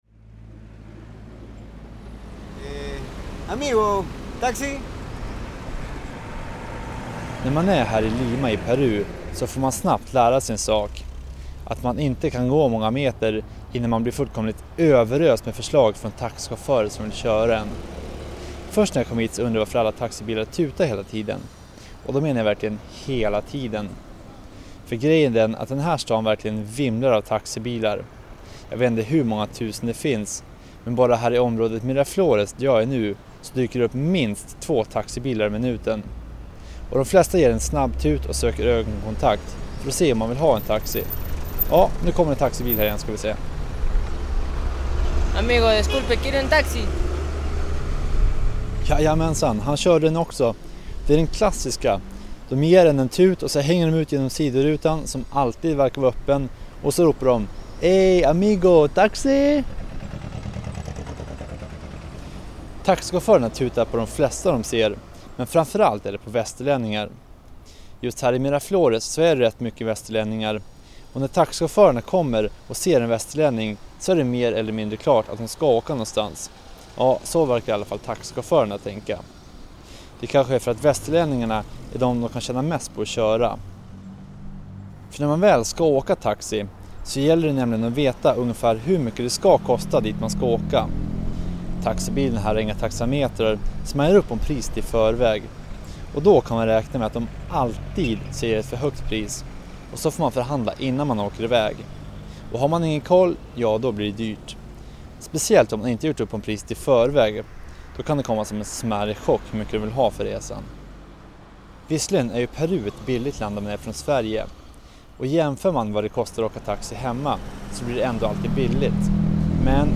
Taxichaufförerna i Perus huvudstad Lima är minst sagt offensiva i sin jakt på kunder. Som västerlänning får man räkna med att ständigt höra och se taxichaufförer som tutar och hänger ut genom fönstren och ropar på en för att försöka locka in till en åktur.
Reportaget sändes i programmet P3 Planet.